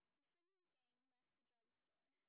sp18_street_snr20.wav